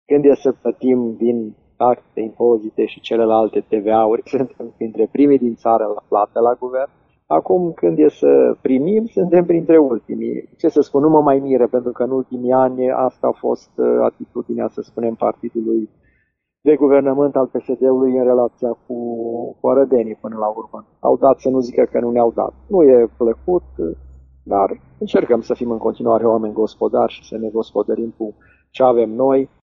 Primarul Călin Bibarţ: